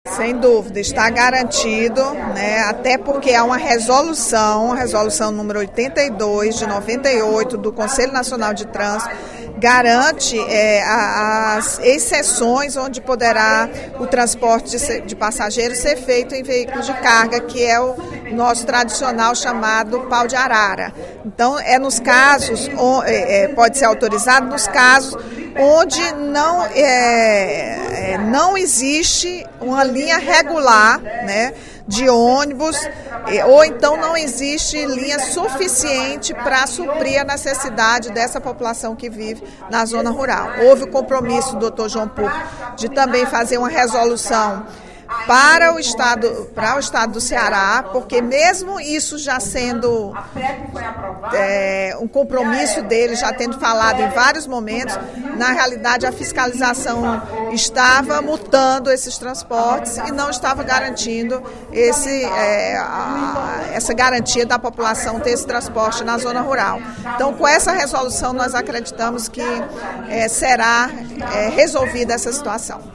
A deputada Rachel Marques (PT) disse, durante pronunciamento na sessão plenária desta quarta-feira (23/05), que o transporte de passageiros em veículo de carga está garantido e os paus-de-arara vão poder circular nas cidades do Interior não atendidas por linha regular de ônibus.